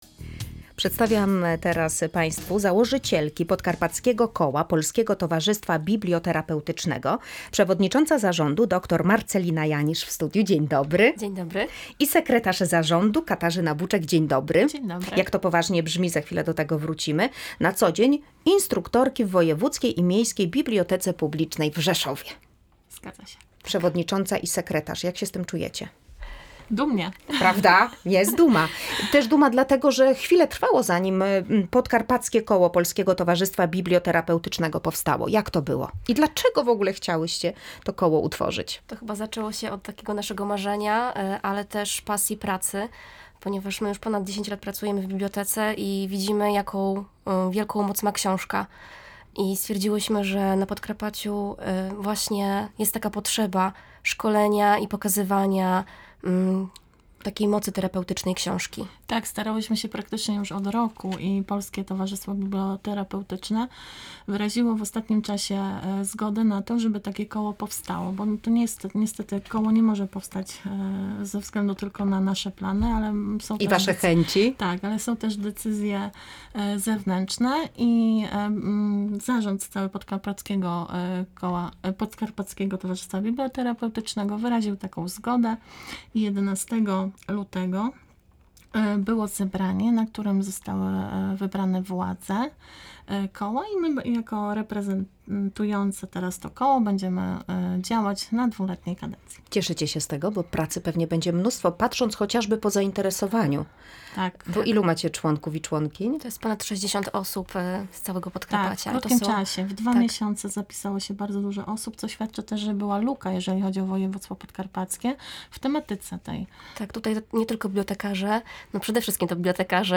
Szczegóły w rozmowie